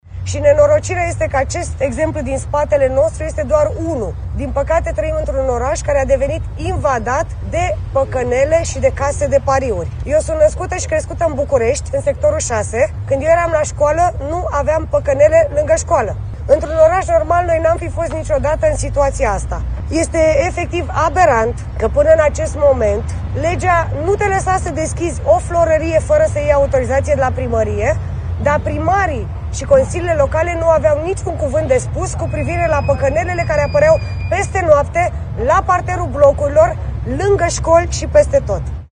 Mai mulți membri USR, într-o conferință de presă ținută într-un cartier de blocuri, au ținut în mâini pancarde cu mesajele: „Fără păcănele lângă școli” și „Jocurile de noroc distrug vieți”.